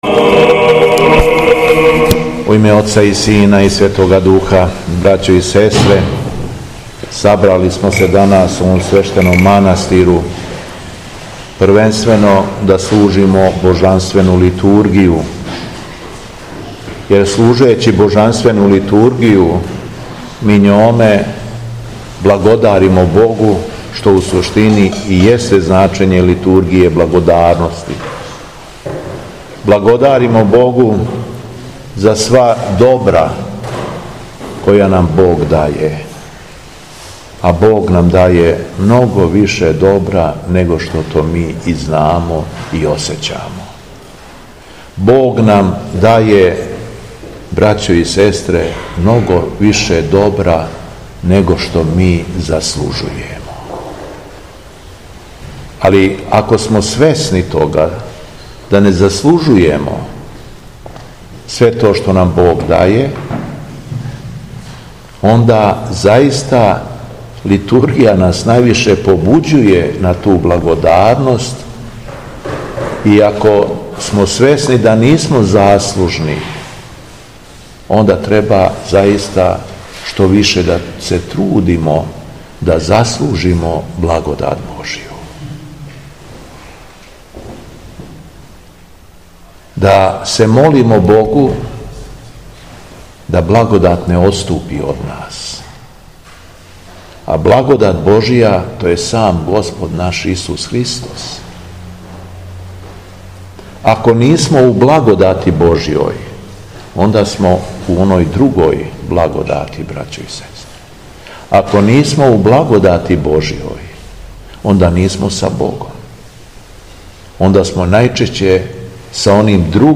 Беседа Његовог Преосвештенства Епископа шумадијског г. Јована
После прочитаног Јеванђелског зачала, епископ се обратио сабраном народу, рекавши: